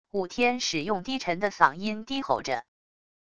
武天使用低沉的嗓音低吼着wav音频